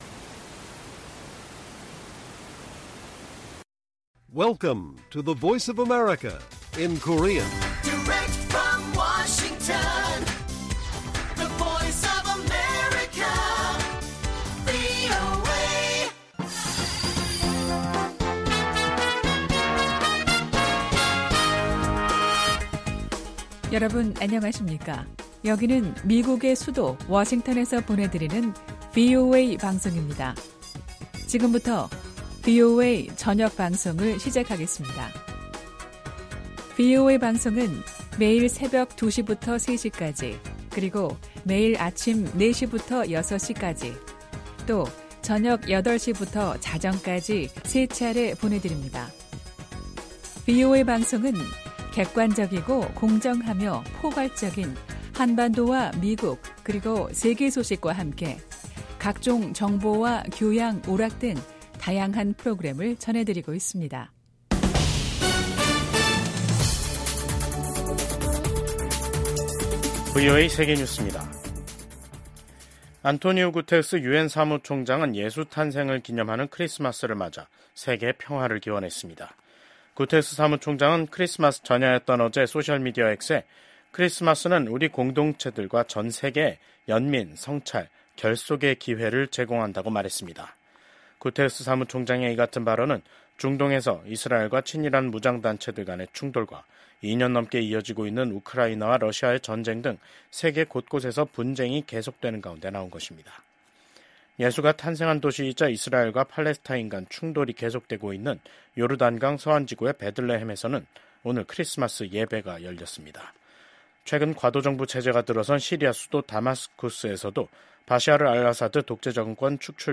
VOA 한국어 간판 뉴스 프로그램 '뉴스 투데이', 2024년 12월 25일 1부 방송입니다. 한국의 계엄과 탄핵 사태로 윤석열 대통령이 미국과 공조해 추진해 온 미한일 3국 협력이 지속 가능하지 않을 수 있다고 미 의회조사국이 평가했습니다. 미국과 한국 정부가 한국의 비상계엄 사태로 중단됐던 양국의 외교안보 공조 활동을 재개키로 했습니다. 일본에서 발생한 대규모 비트코인 도난 사건이 북한 해커집단의 소행으로 밝혀졌습니다.